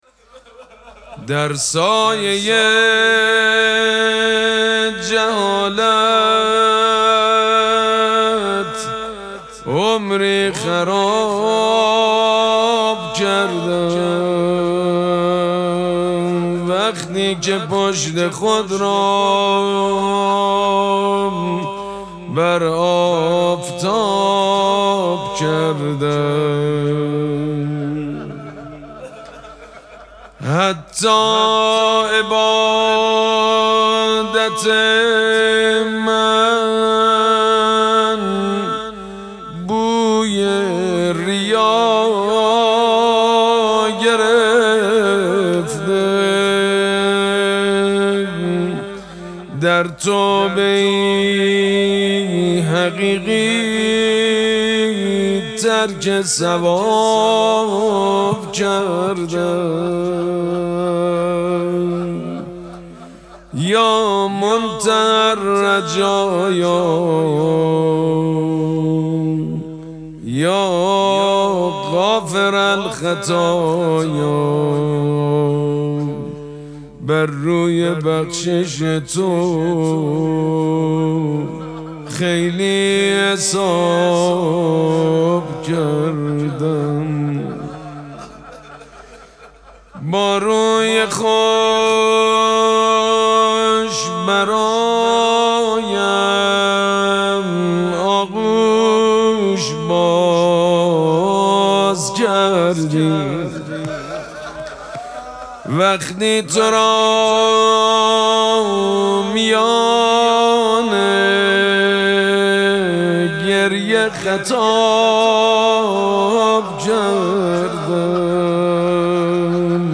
مراسم مناجات شب هفتم ماه مبارک رمضان
مناجات
حاج سید مجید بنی فاطمه